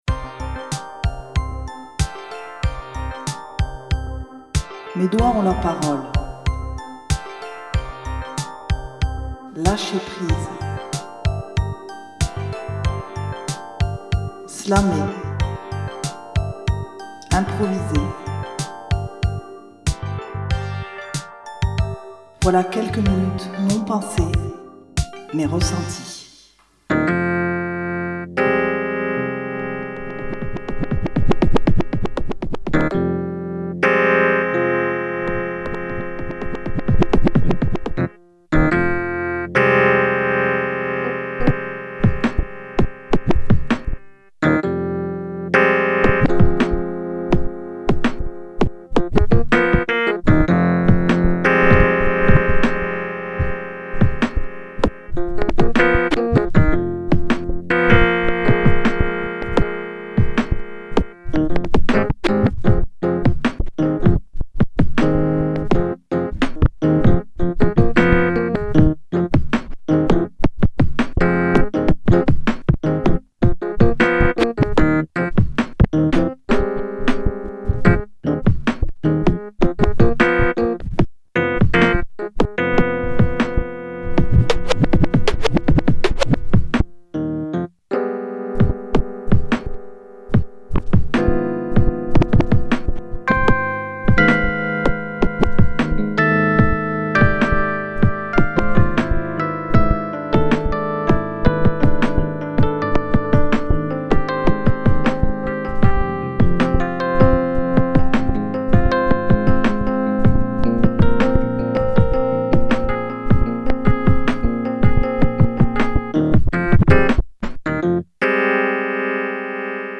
5e épisode d'improvisation au clavier
au scratch